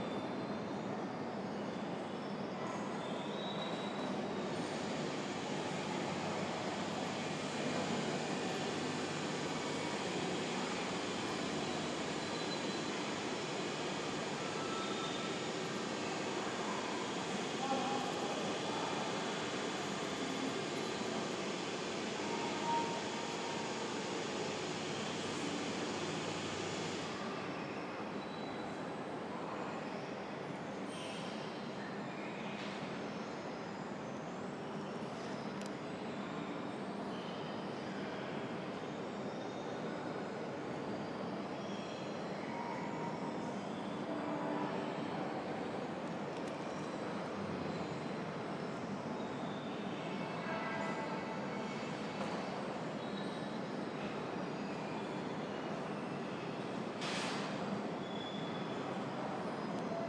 60 seconds of: Stansted Sirport train station ambience